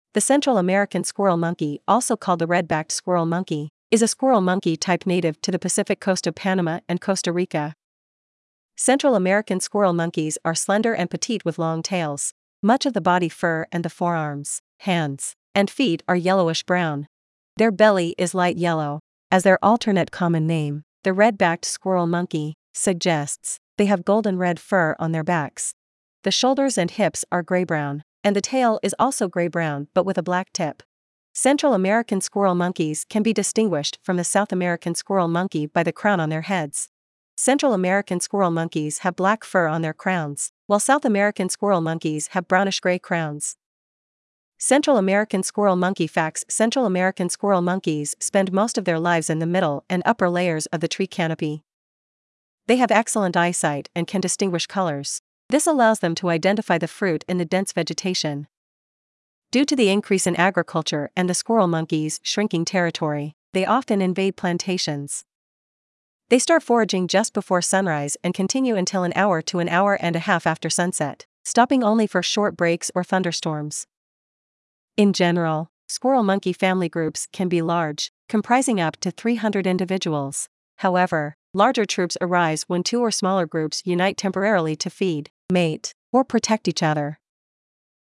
Central American Squirrel Monkey
Central-American-Squirrel-Monkey.mp3